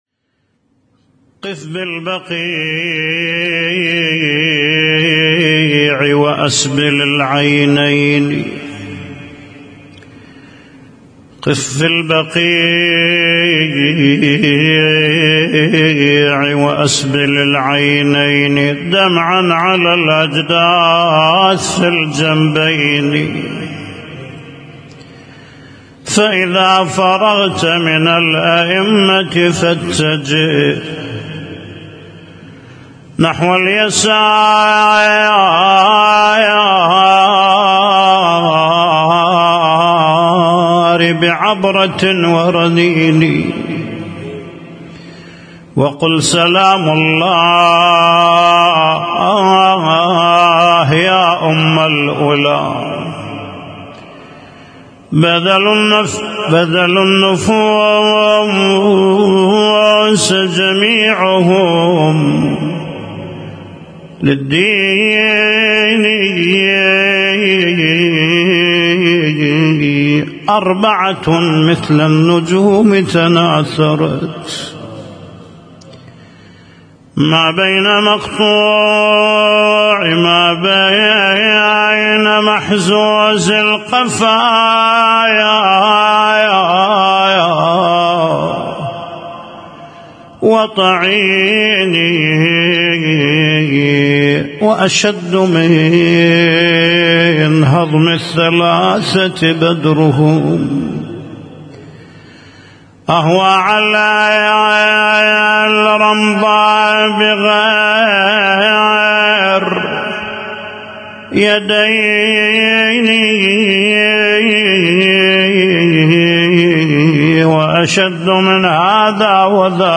Husainyt Alnoor Rumaithiya Kuwait
اسم التصنيف: المـكتبة الصــوتيه >> الصوتيات المتنوعة >> النواعي